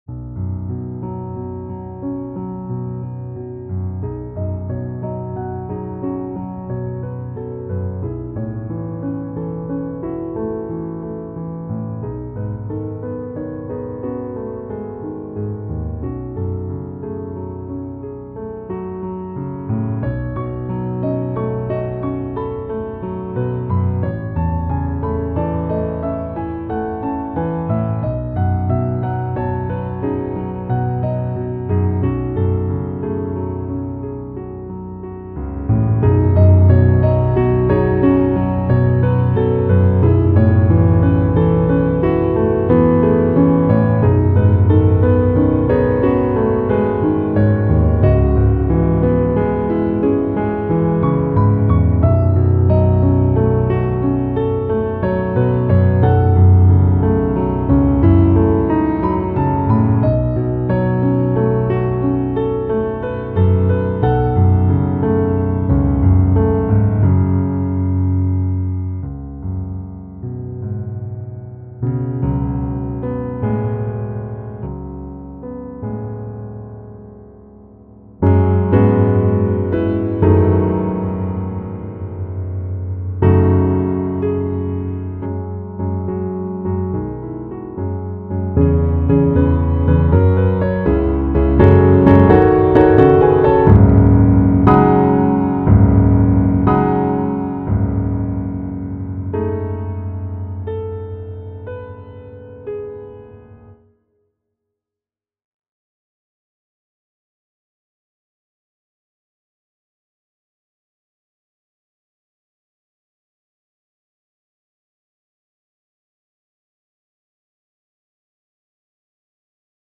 Impressionistic practice
This time I wanted to share with you a modal practice my teacher asked for!!